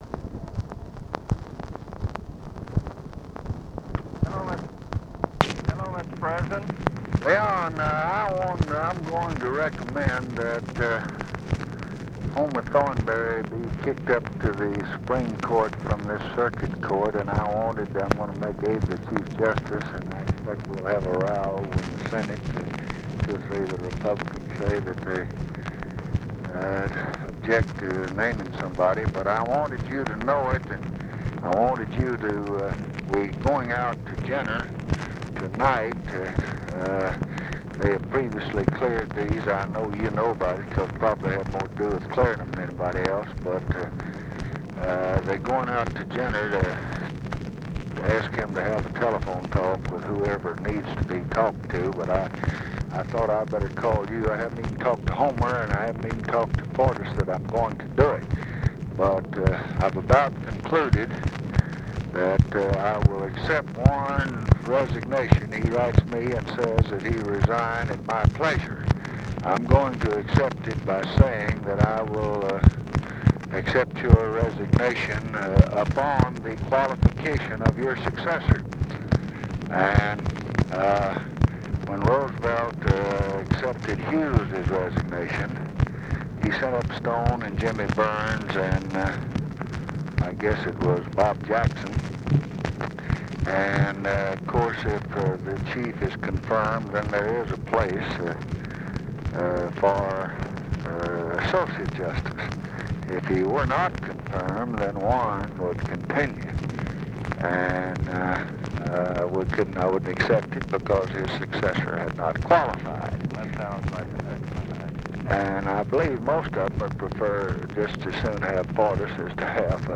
Conversation with LEON JAWORSKI, June 25, 1968
Secret White House Tapes